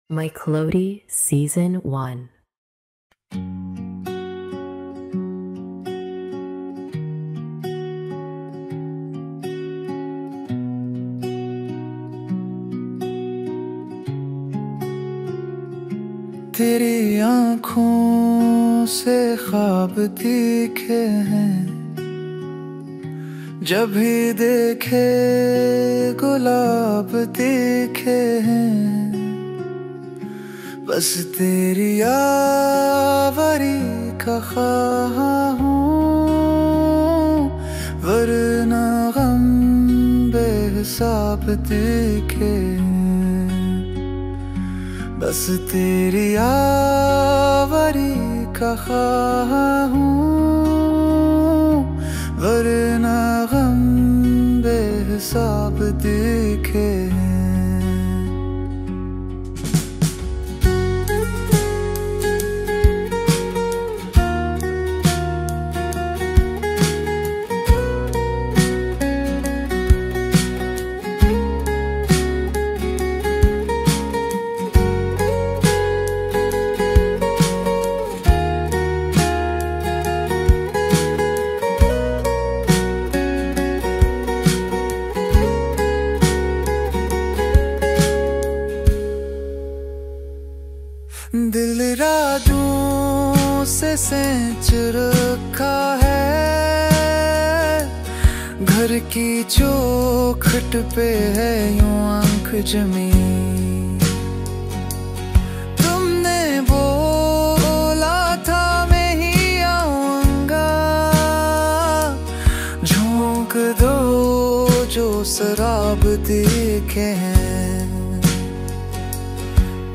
•  Lyrics born from the heart, melodies crafted with AI.